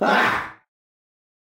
男性尖叫声
描述：男性的尖叫声
标签： 男孩 家伙 尖叫
声道立体声